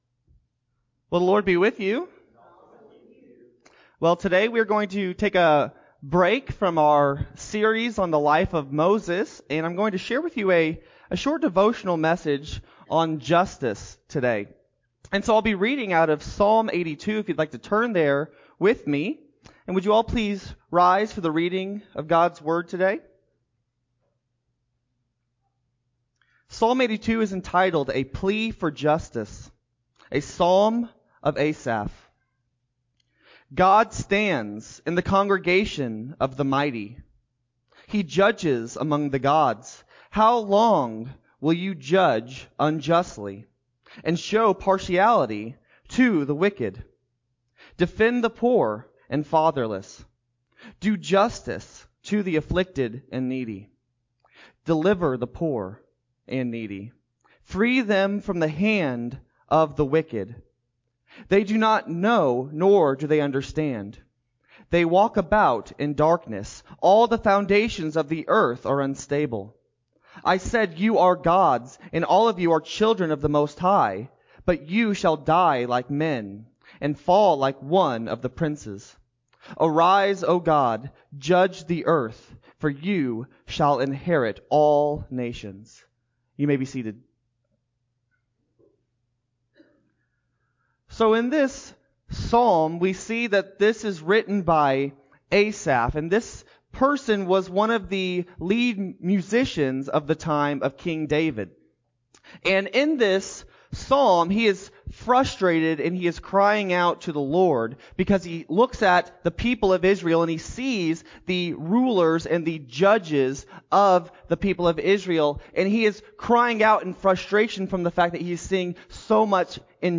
6-13-21-Sermon-CD.mp3